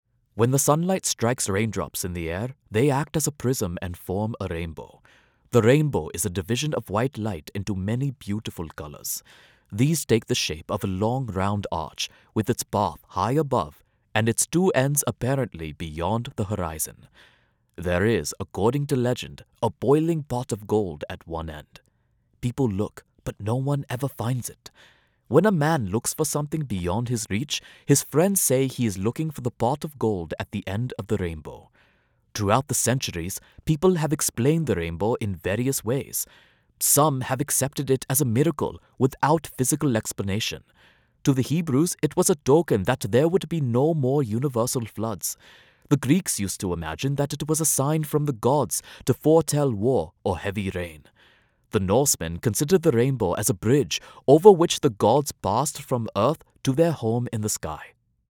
indian | character
indian | natural
broadcast level home studio